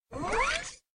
AK_ActiveMechanism.wav